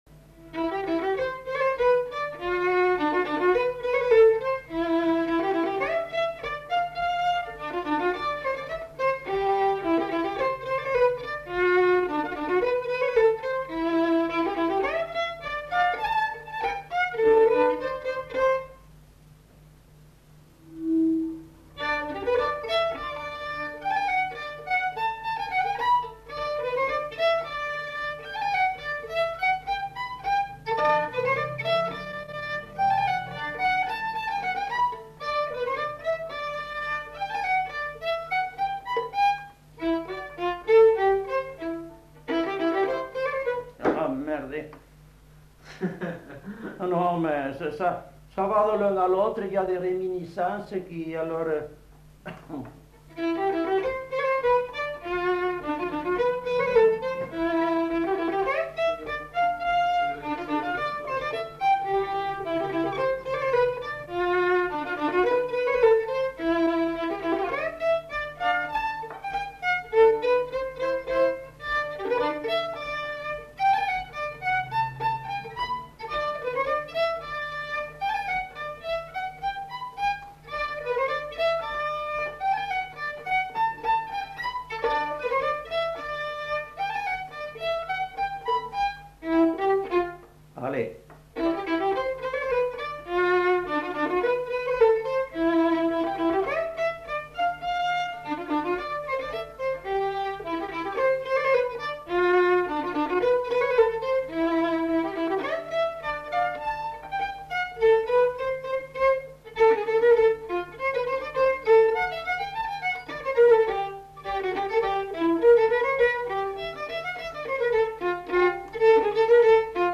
Aire culturelle : Gabardan
Genre : morceau instrumental
Instrument de musique : violon
Danse : polka
Notes consultables : Plusieurs thèmes mais enchaînement confus.